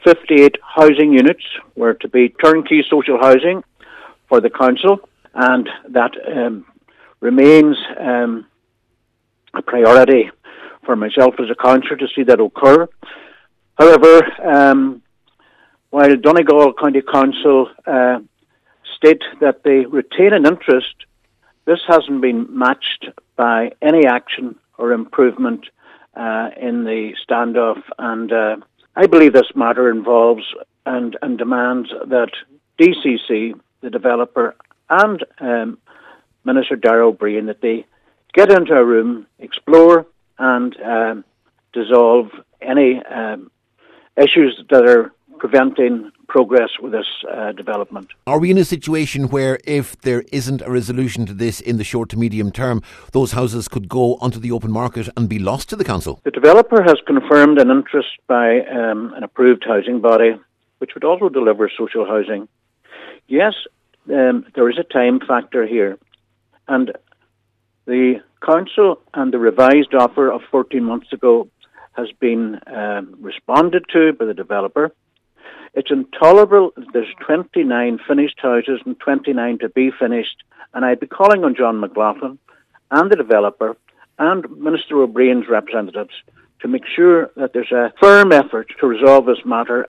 Cllr Albert Doherty says this is a situation that must be addressed, and with half the houses now complete, it’s unacceptable that they are lying empty during a housing crisis………….